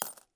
coin_coin_1.ogg